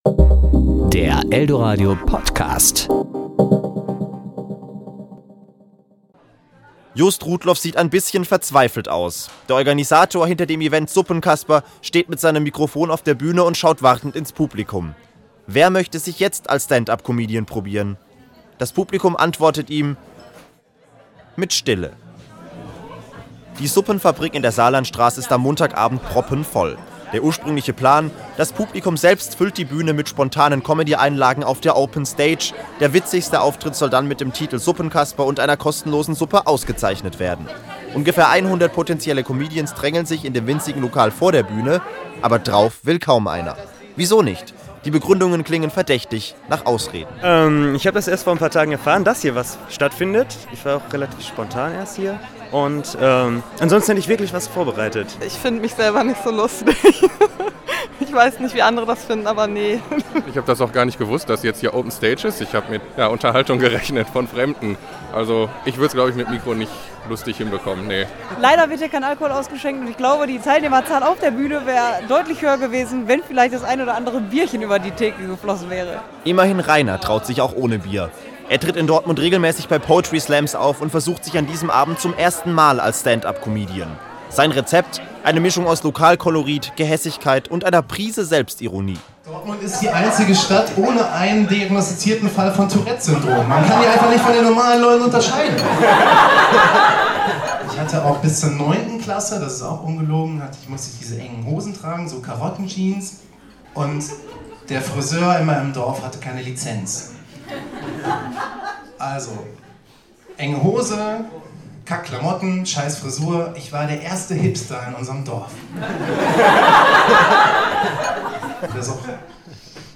Ein bewährtes Konzept in einer ungewöhnlichen Location scheint nicht immer zu funktionieren. In einem Suppenrestaurant an der Saarlandstraße sollte es Lacher ohne Ende von der Open Stage regnen.